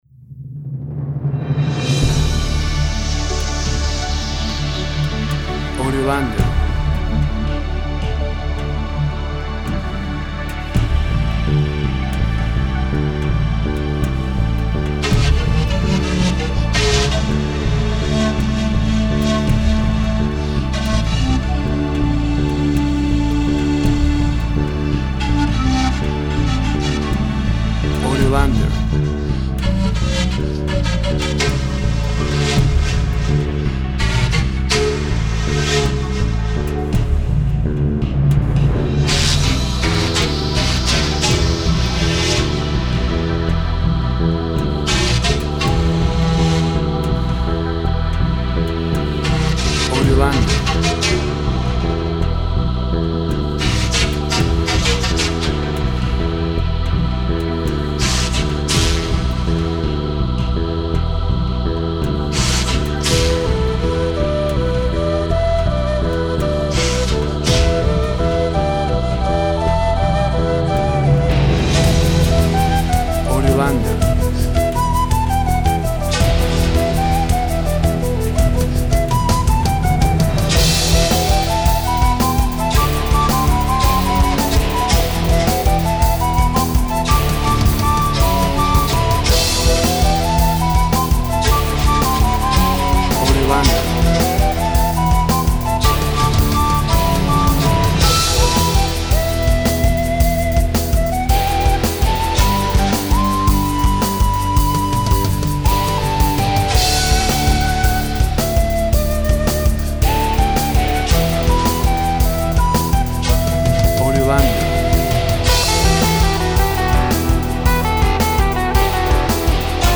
Tempo (BPM) 110